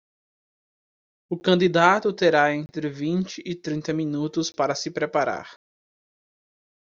Pronounced as (IPA) /kɐ̃.d͡ʒiˈda.tu/